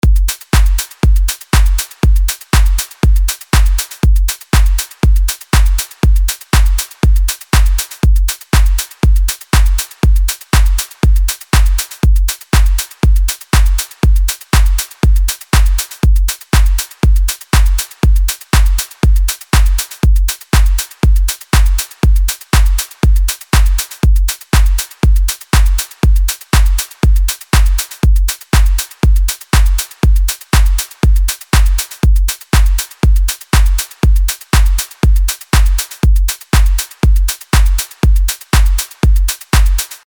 LP 205 – DRUM LOOP – HOUSE – 120BPM